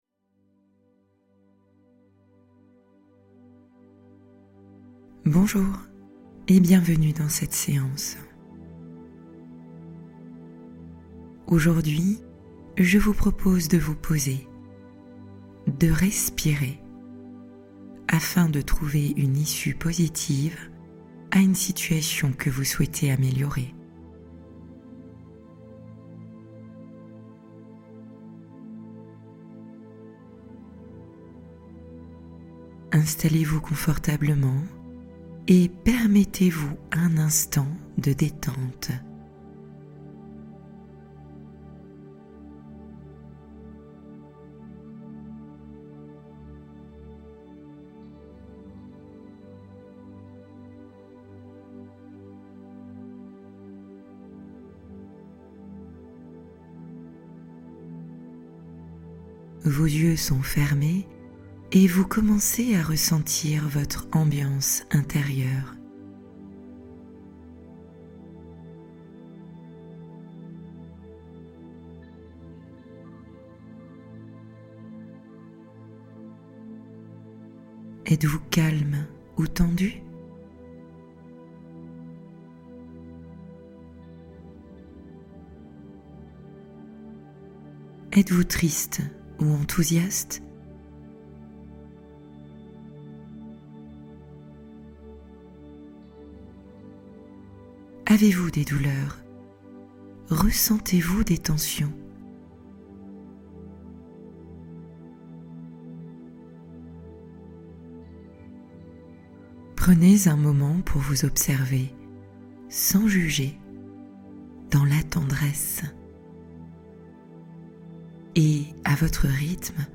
La solution est en vous : Méditation guidée pour trouver la réponse et calmer vos inquiétudes